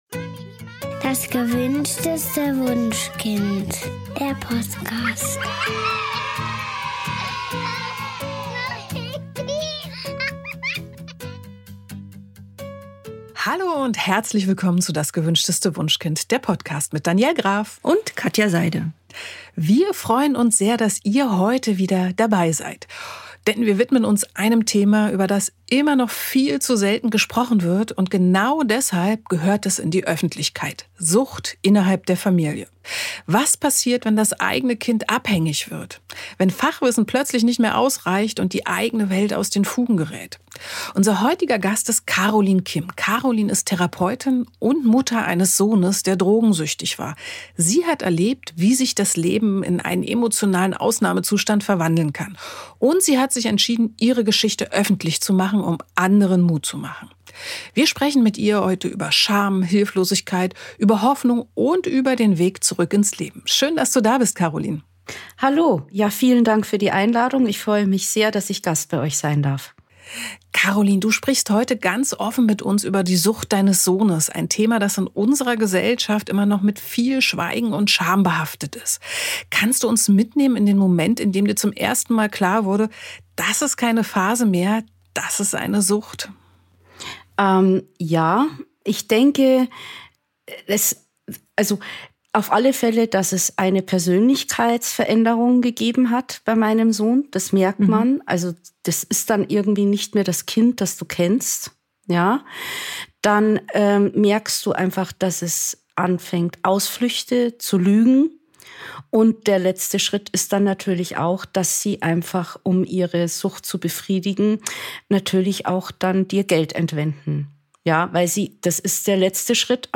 Ein ehrliches Gespräch über Scham, Verantwortung, Hoffnung – und darüber, was uns in Krisen wirklich trägt.